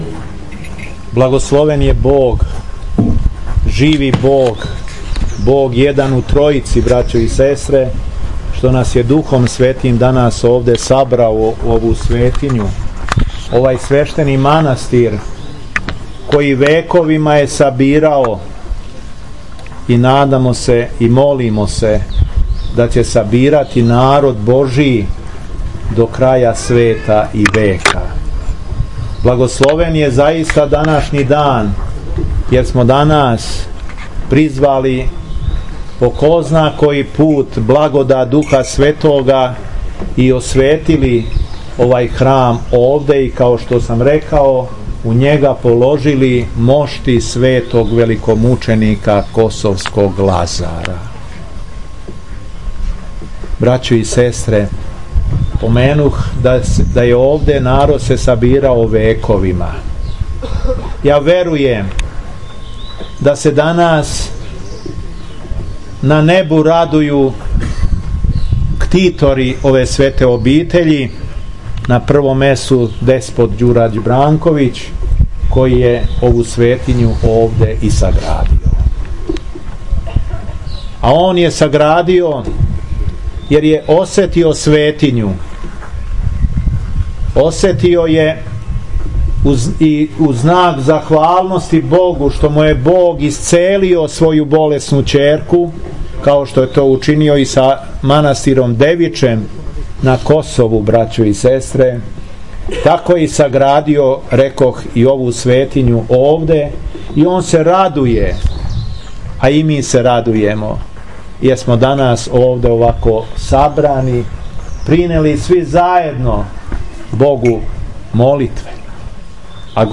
Беседа Епископа шумадијског Г. Јована
После освећења храма, Епископ је одслужио Свету Литургију у манастирској порти.